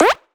cartoon_boing_jump_01.wav